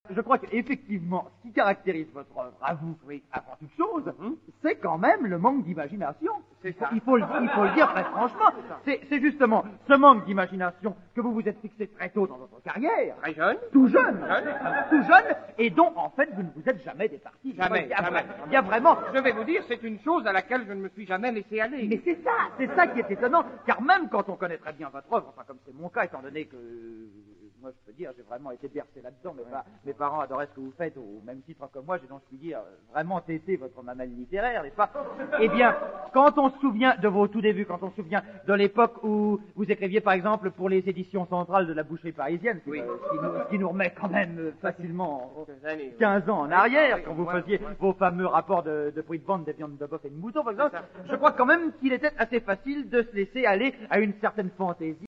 L'écrivain Stéphane Brinville vient de recevoir le prix Gilles pour son roman Minuit moins le quart. Il a tout de suite accepté de donner un entretien à la presse radio-diffusée.
L'Interview de Stéphane Brinville, prix littéraire (14'44") est extrait du Cd audio : Poiret Serrault, nos premiers sketches (Edition Isis, 2006).